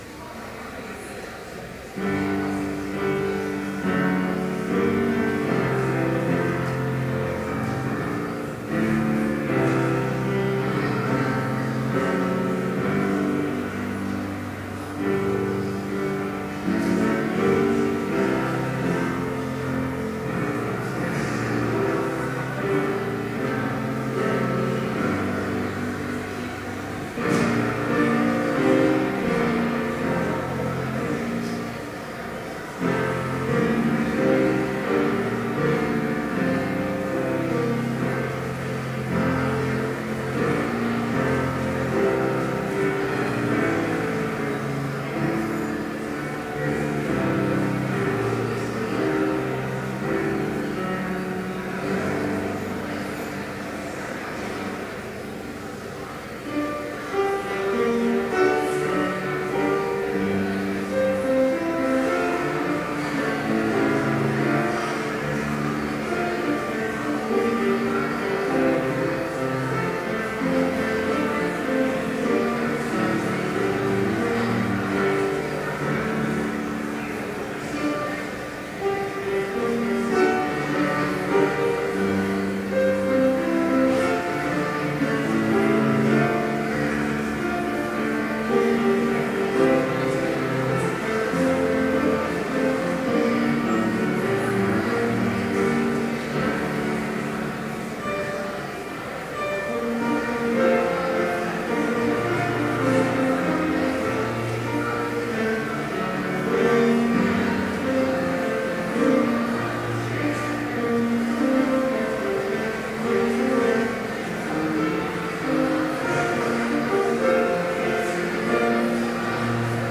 Complete service audio for Chapel - September 11, 2012